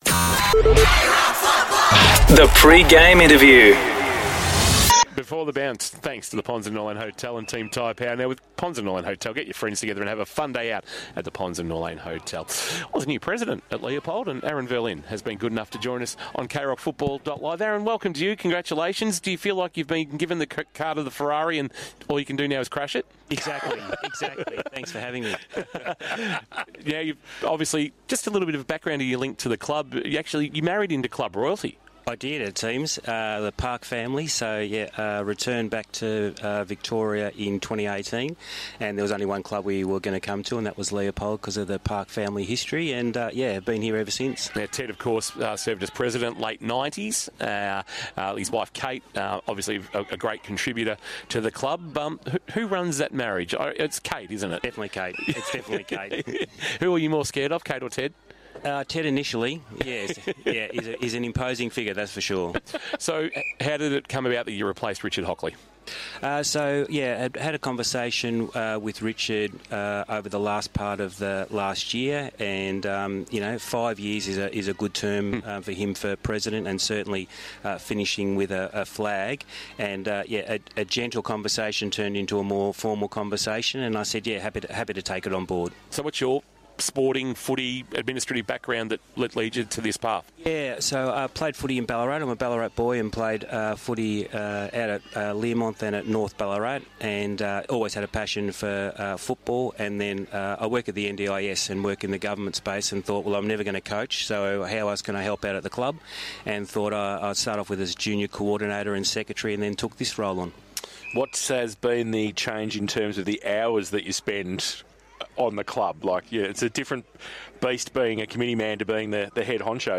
Pre-match interview